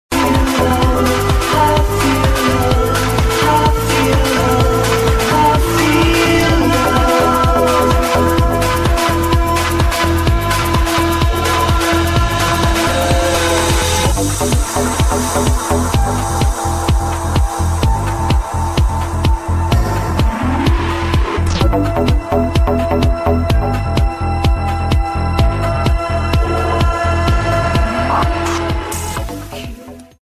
Proggy Tune Needs ID!
This is a rip from Frisky Radio, This track was also played by Pete Tong alot over the summer.